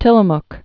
(tĭlə-mk)